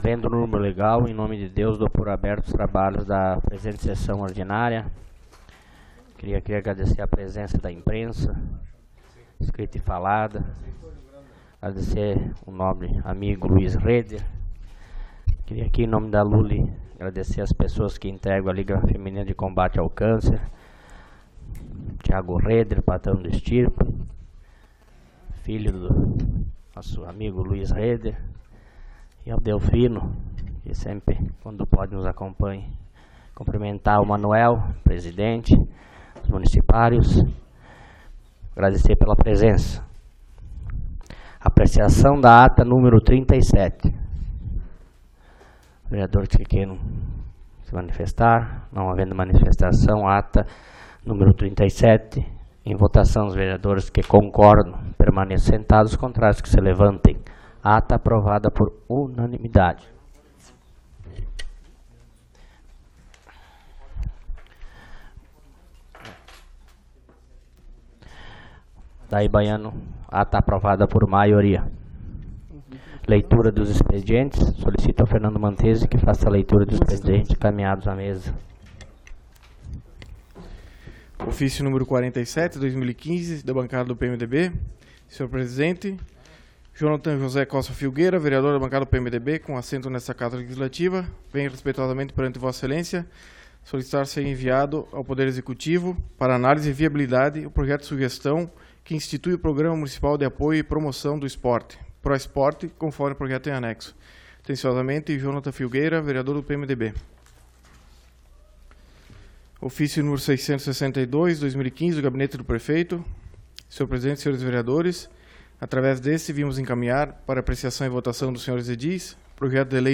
Publicação: 21/12/2015 às 00:00 Abertura: 21/12/2015 às 00:00 Ano base: 2015 Número: Palavras-chave: Sessão Ordinária do dia 21 de Dezembro de 2015 - PARTE 1.